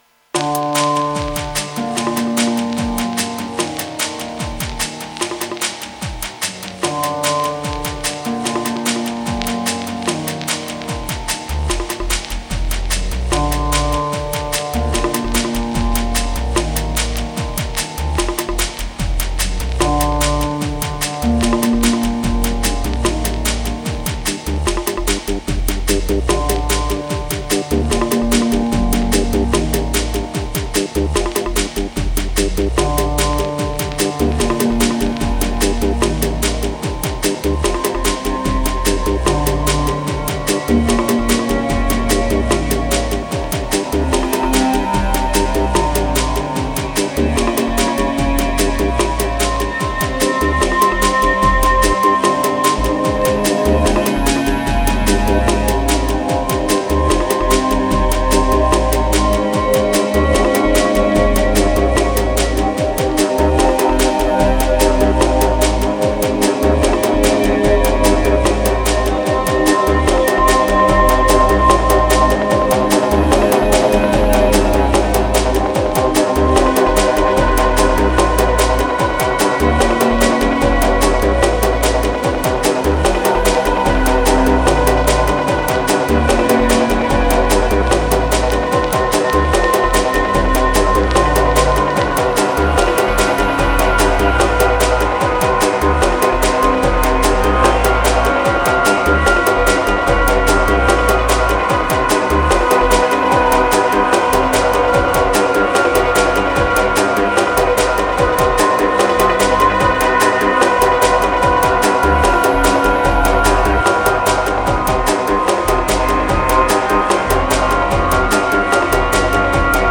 Mid-day, nappy riddim.